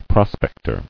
[pros·pec·tor]